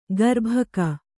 ♪ garbhaka